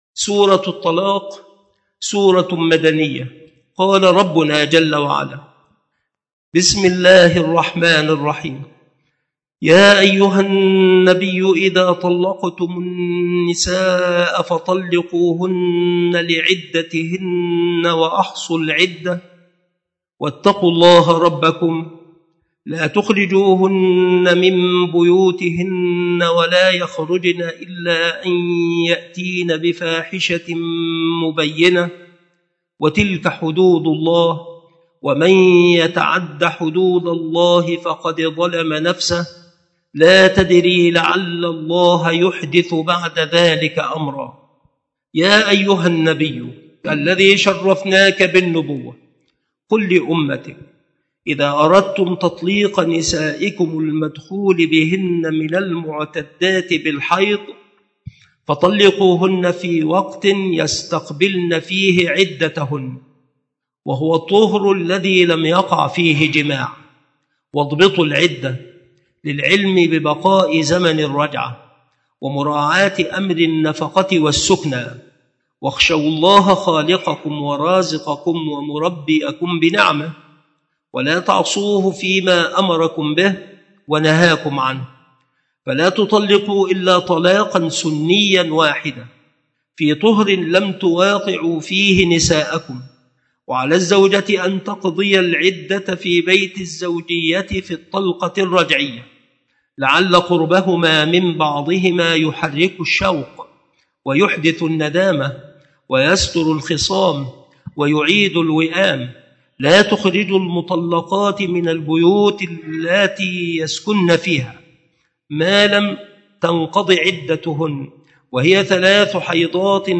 التفسير